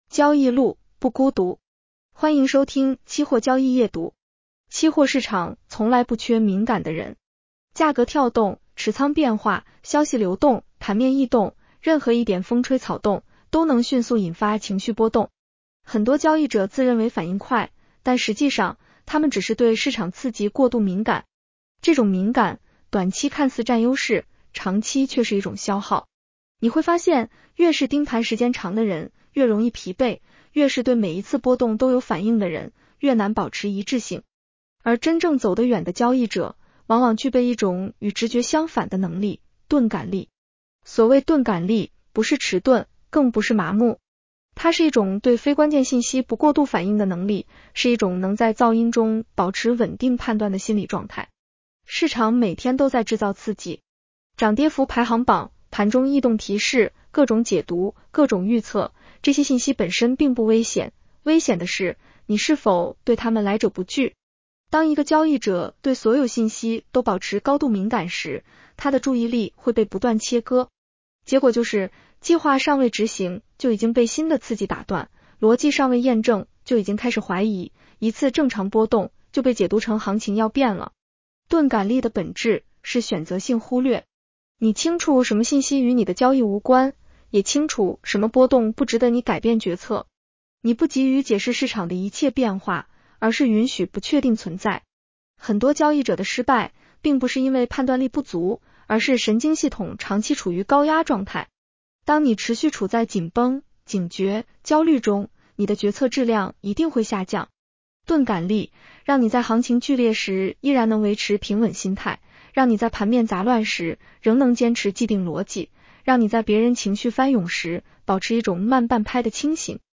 女声普通话版 下载mp3
（AI生成）